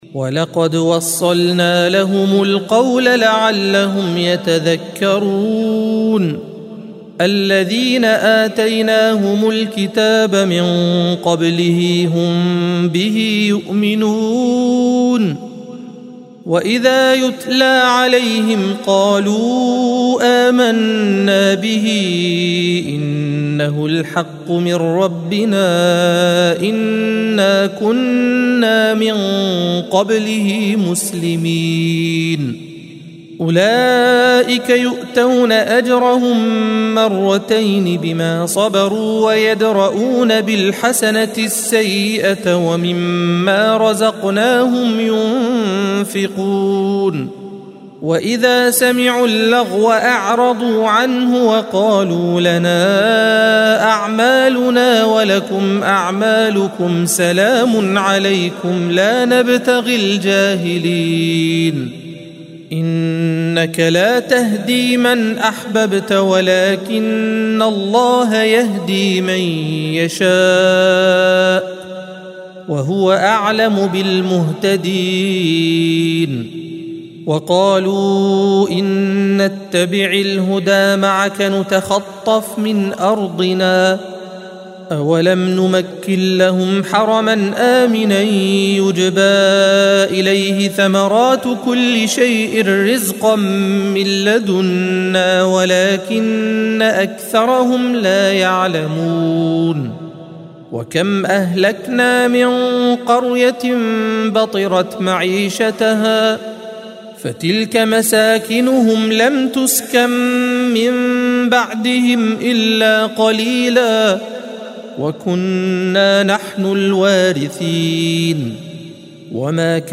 الصفحة 392 - القارئ